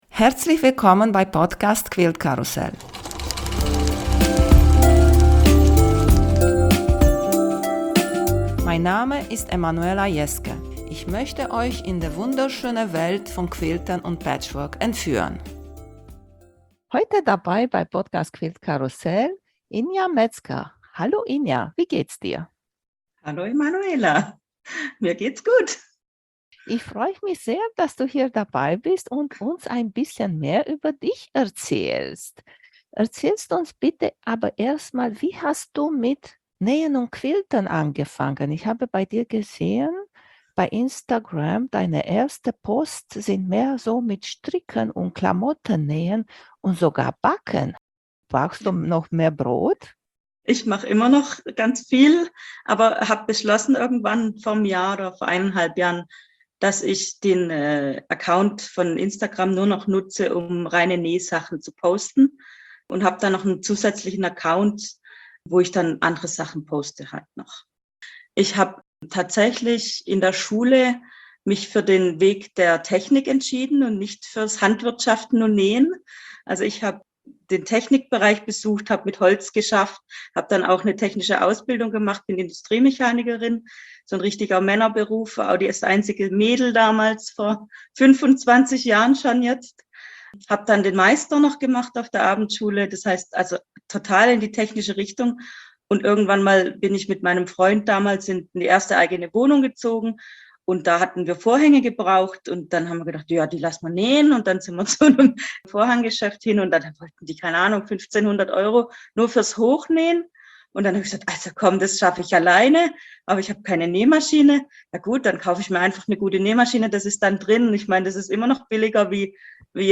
Der Interview-Podcast für Quilten, Patchwork und Nähen.